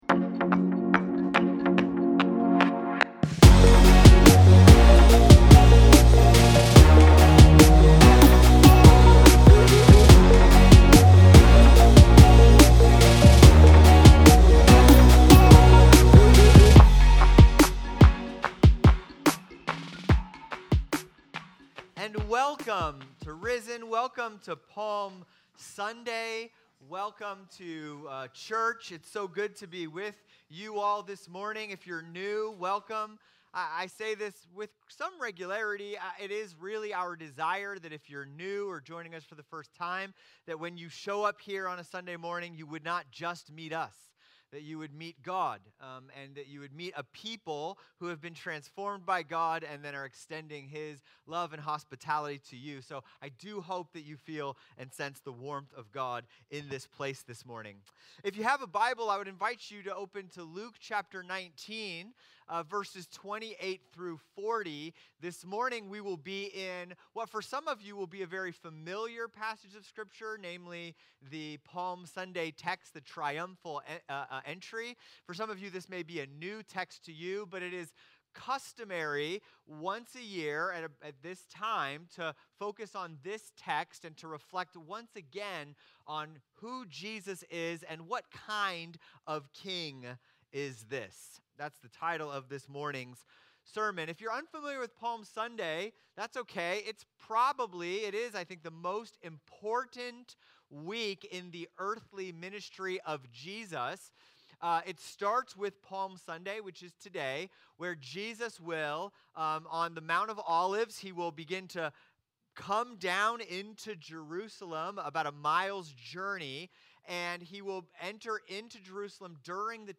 Sermons | RISEN CHURCH SANTA MONICA, INC